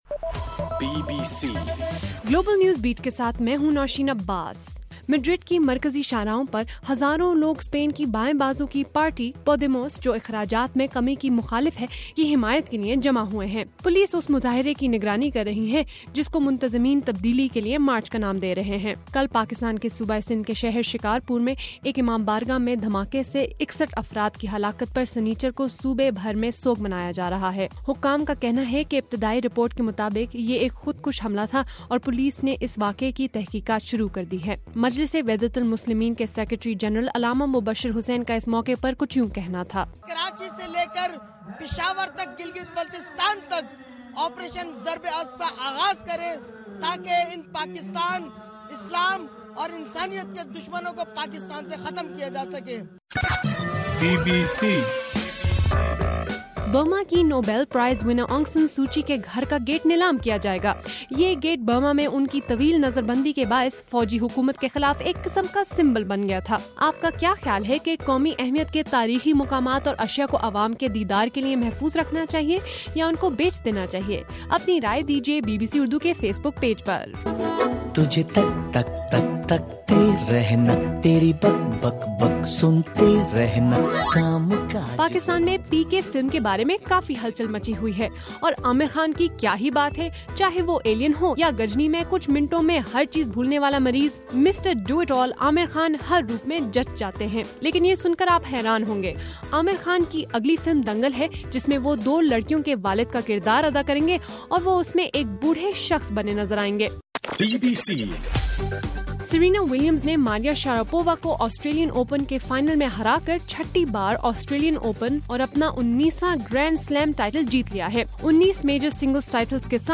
جنوری 31: رات 10 بجے کا گلوبل نیوز بیٹ بُلیٹن